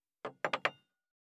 216,スッ,サッ,コン,ペタ,パタ,チョン,コス,カラン,ドン,チャリン,効果音,環境音,BGM,
コップ効果音物を置く
コップ